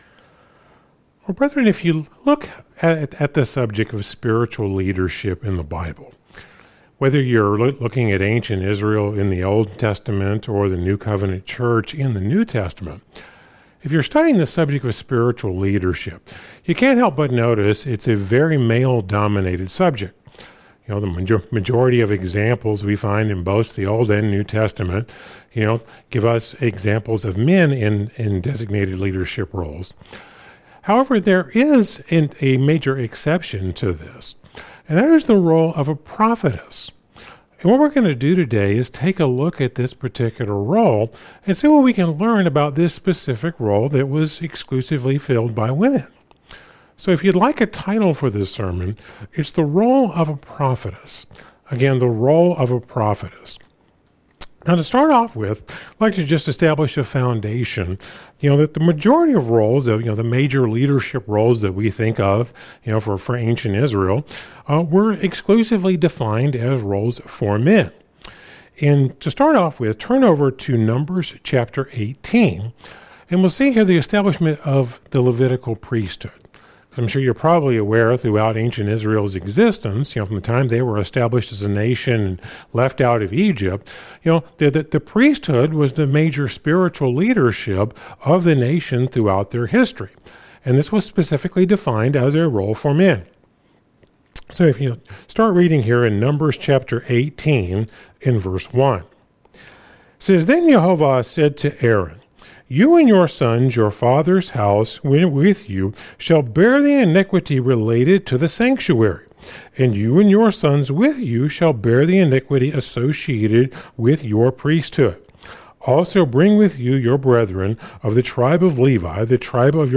Sermons – Searching The Scriptures